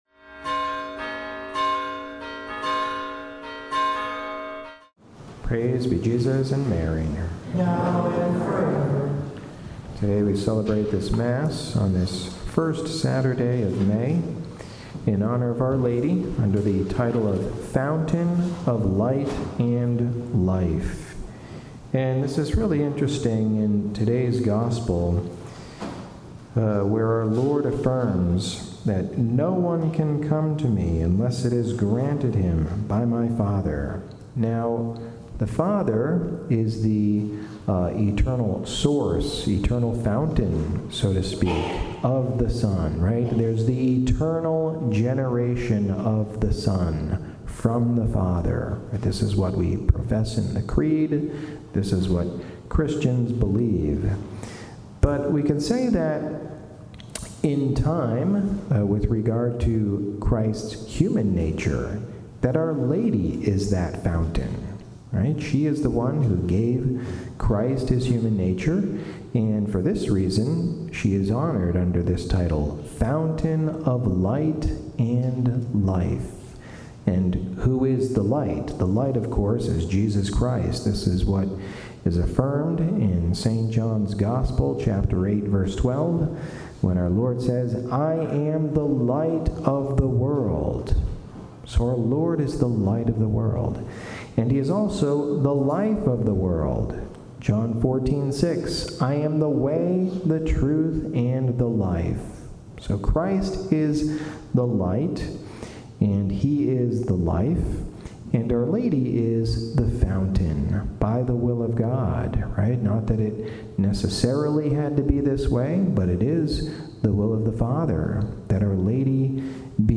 Mary: Fount of Light & Life- May 07 – Homily